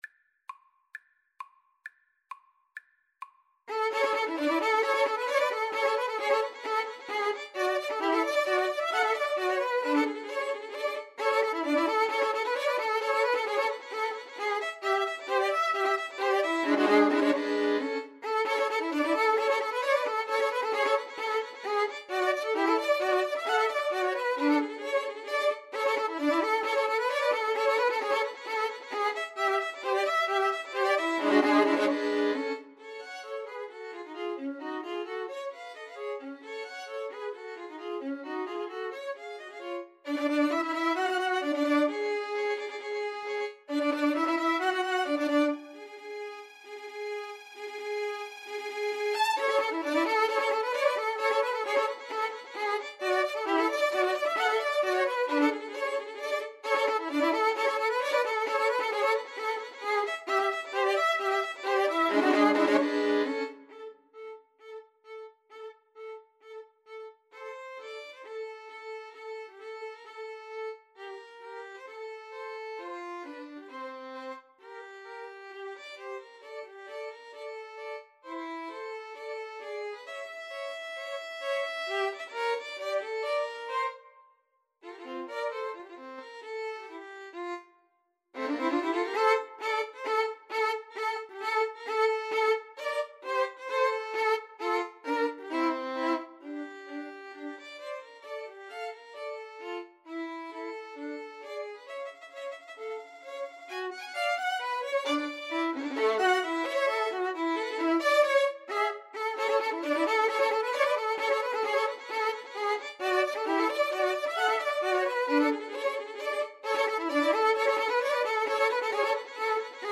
2/4 (View more 2/4 Music)
Violin Trio  (View more Intermediate Violin Trio Music)
Classical (View more Classical Violin Trio Music)